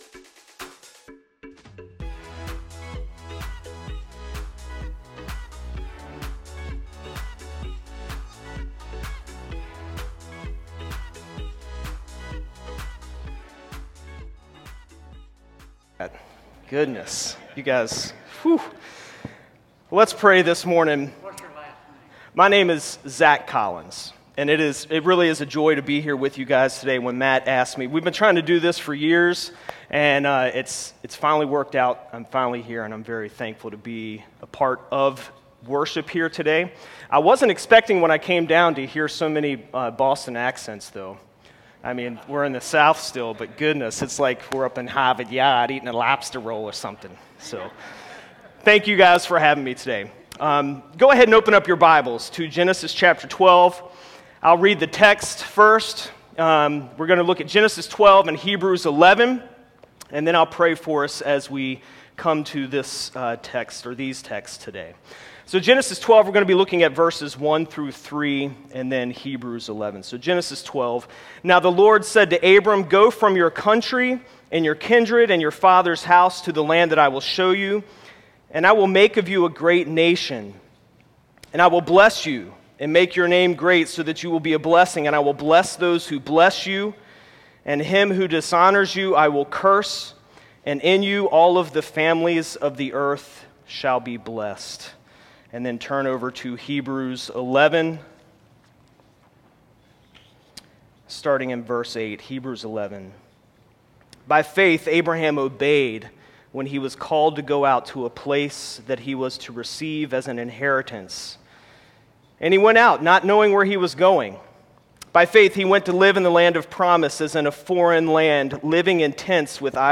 Sermon10_03_Blessed-to-be-a-Blessing.m4a